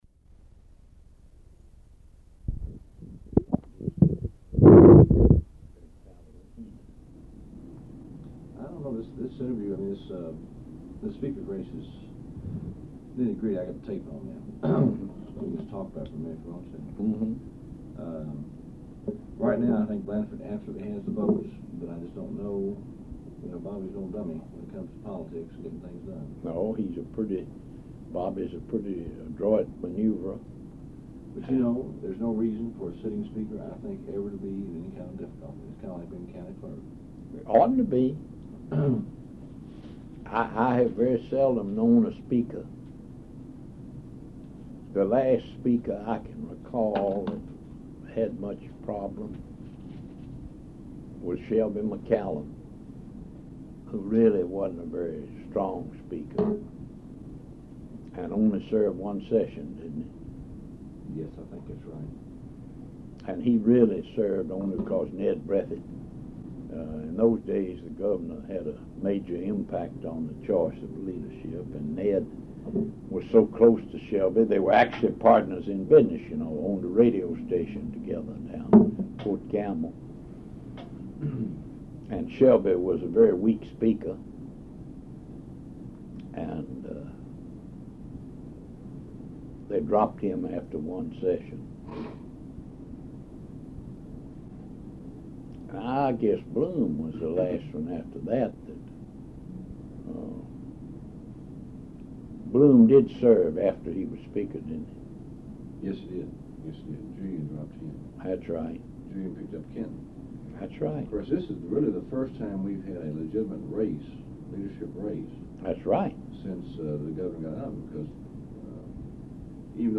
Oral History Interview with Edward F. Prichard, Jr., October 31, 1984 Part 1